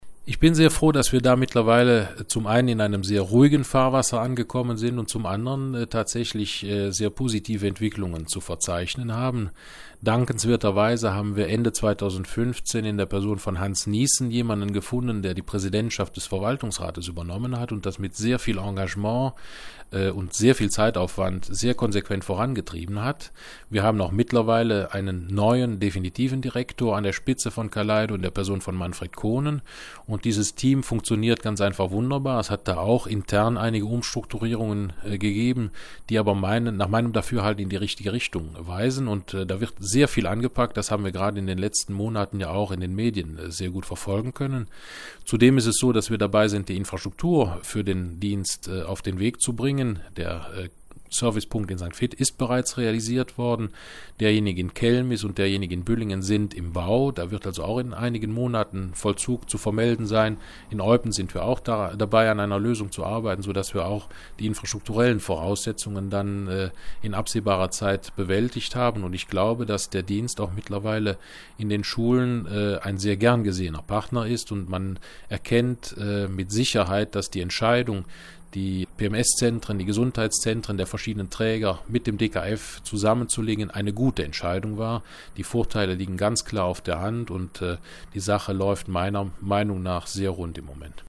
Minister Harald Mollers, 04.09.`17 4.
Dazu der Unterrichtsminister Harald Mollers: